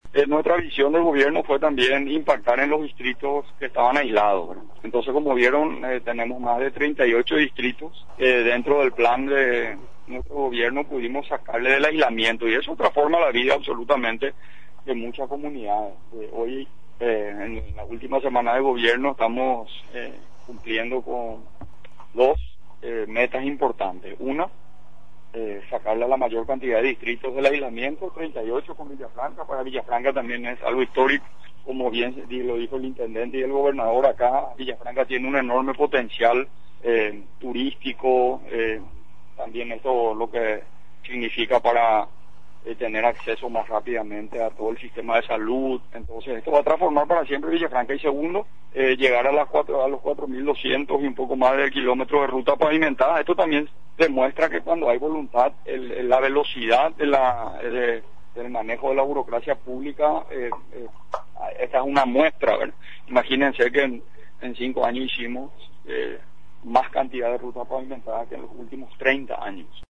Durante su discurso en el acto de habilitación de 25 km de nuevo asfaltado que forma parte de la Ruta Alberdi – Pilar tramo 1 y el acceso a Villa Franca, realizado este lunes, el presidente de la República, Mario Abdo Benítez, destacó que en el período de su Gobierno, se procedió a la habilitación de más de 4200 kilómetros de rutas pavimentadas.